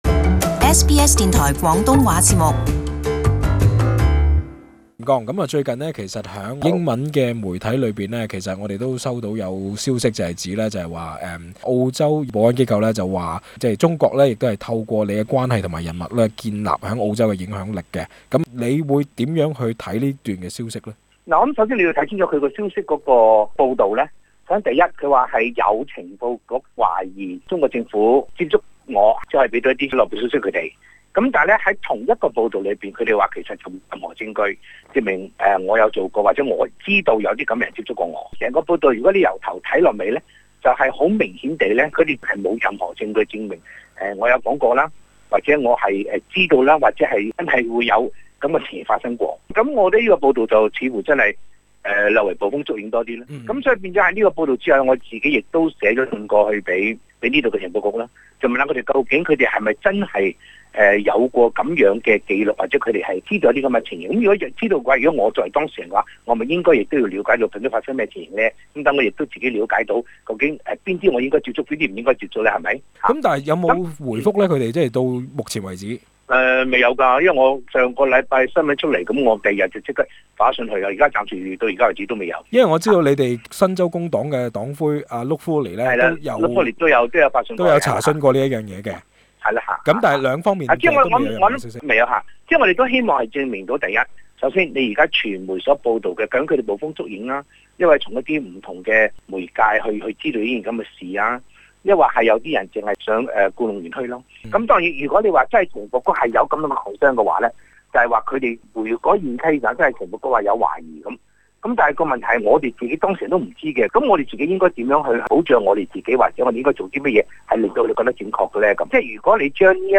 【時事專訪】王國忠如何回應被指與中國「過從甚密」？